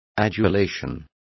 Complete with pronunciation of the translation of adulation.